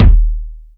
KICK.92.NEPT.wav